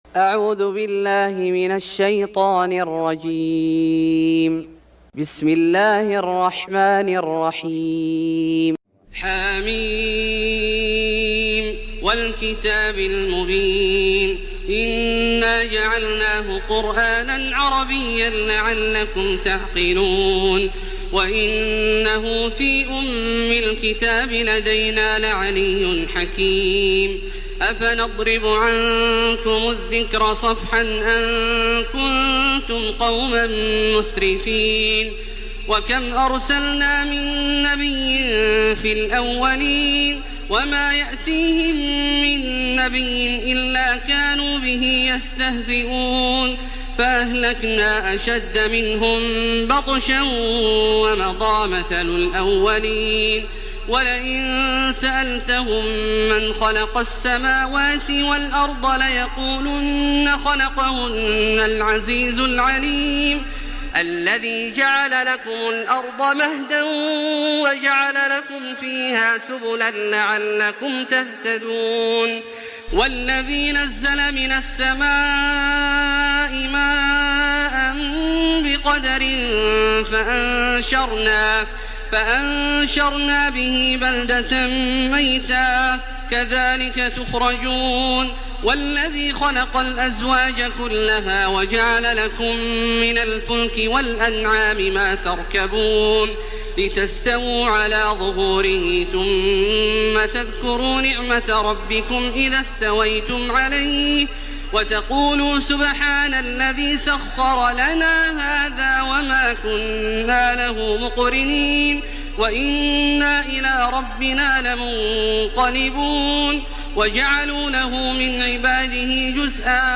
سورة الزخرف Surat Az-Zukhruf > مصحف الشيخ عبدالله الجهني من الحرم المكي > المصحف - تلاوات الحرمين